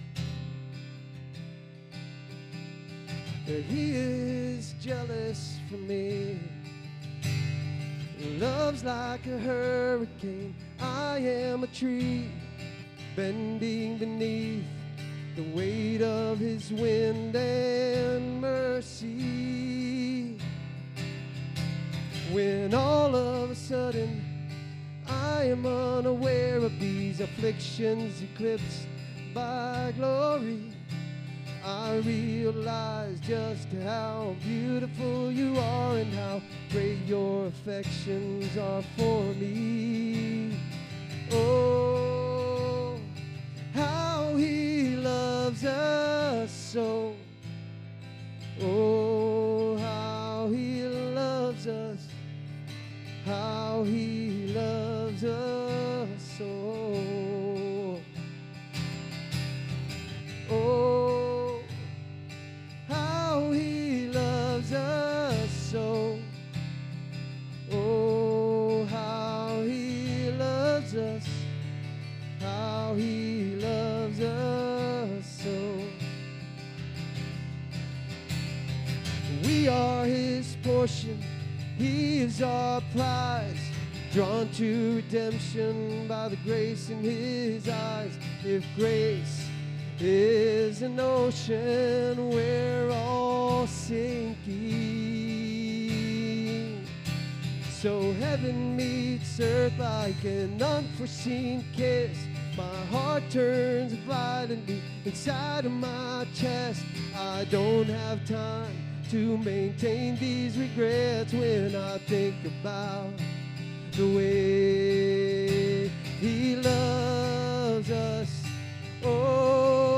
SERMON DESCRIPTION In Mark 9, Jesus redirects the disciples’ misguided passion toward unity in His Kingdom, warning them against division and calling for serious reflection on their hearts and actions.